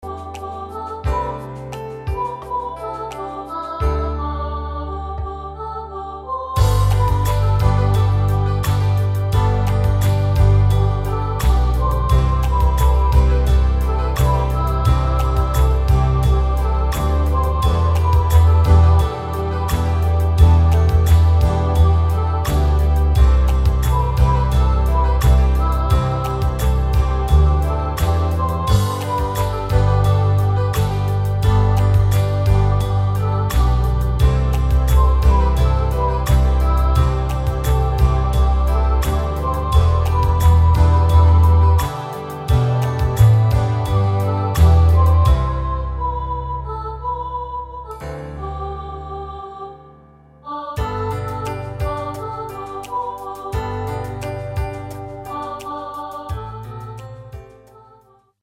Rubrika: Pop, rock, beat
Karaoke